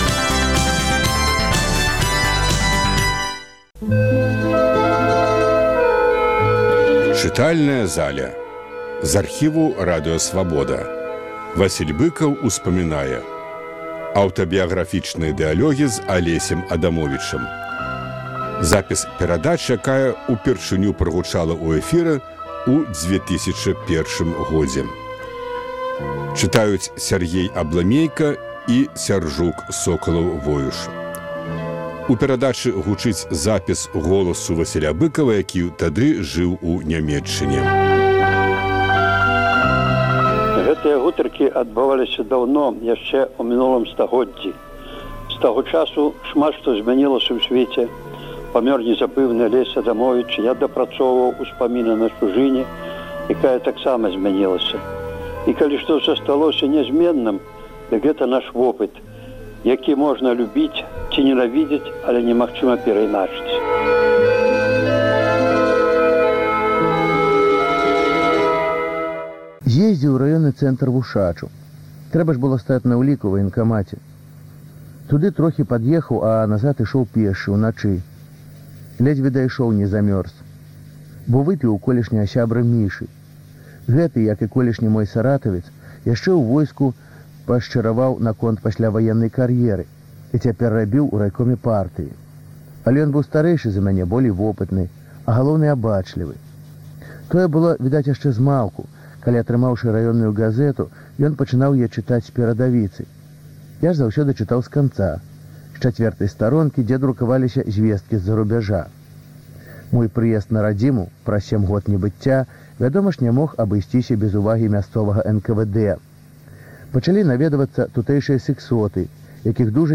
У чытальнай залі Свабоды - успаміны ў аўтарскім чытаньні.